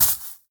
Minecraft Version Minecraft Version latest Latest Release | Latest Snapshot latest / assets / minecraft / sounds / block / nether_sprouts / step3.ogg Compare With Compare With Latest Release | Latest Snapshot
step3.ogg